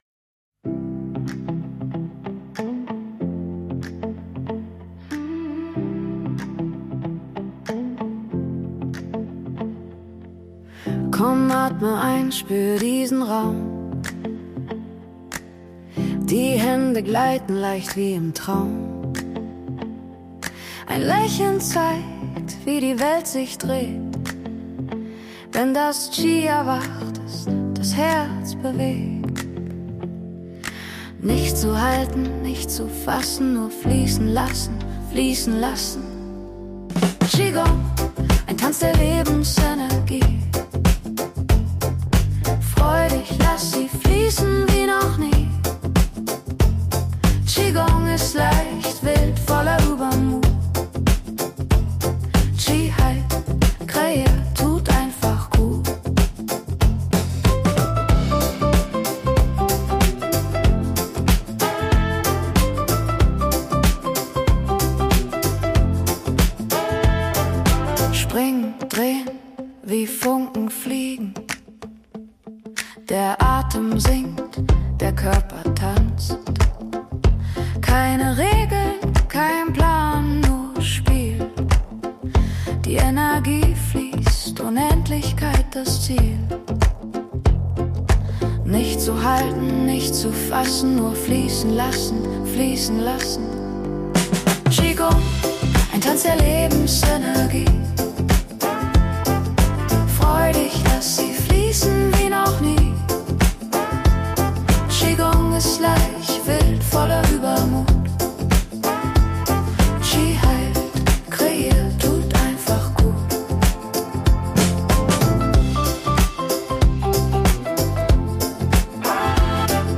Komposition mit Suno AI